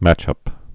(măchŭp)